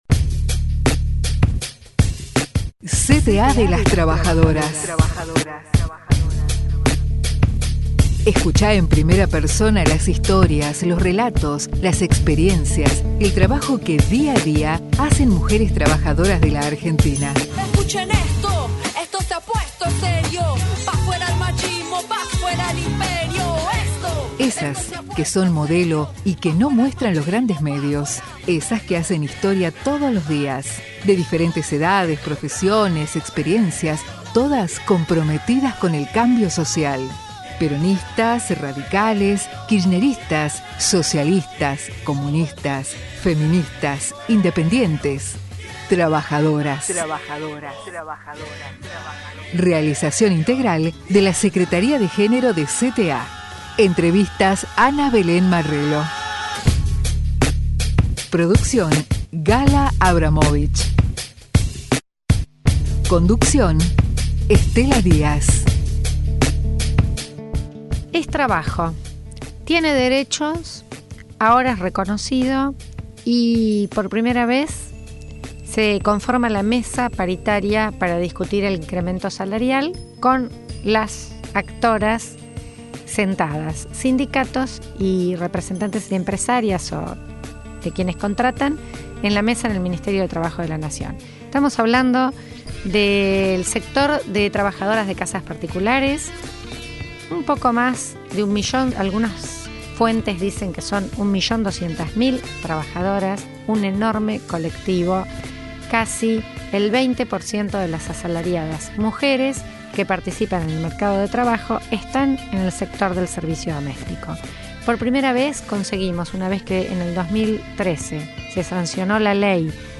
En esta emisión hablamos sobre la paritaria de las trabajadoras domésticas. Hablamos con el interventor del INADI, Pedro Mouratian sobre los 20 años del INADI. También estuvimos hablando con la Diputada Adela Segarra sobre el Encuentro Nacional de Mujeres que se va a realizar los días 10, 11 y 12 de Octubre en Mar del Plata.